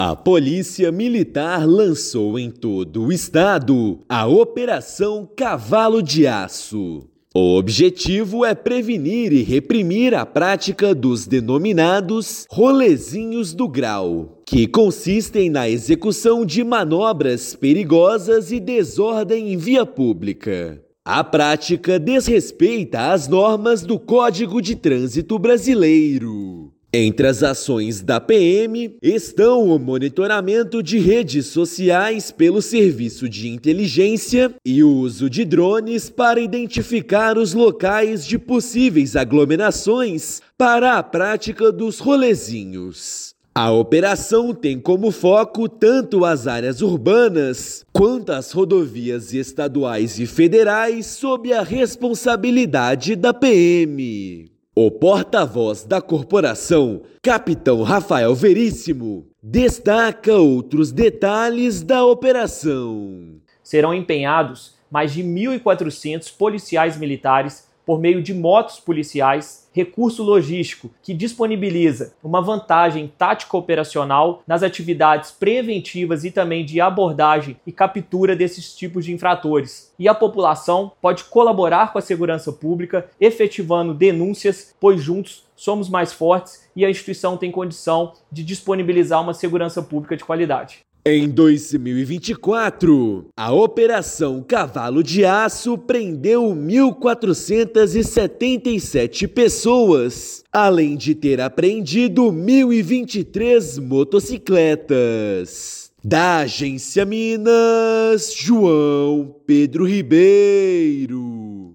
Agência Minas Gerais | [RÁDIO] Polícia Militar lança operação para prevenir e reprimir a prática dos denominados "rolezinhos do grau” no estado
Ação para coibir a prática ilegal vai reunir 1.400 policiais nas áreas urbanas e rodovias. Ouça matéria de rádio.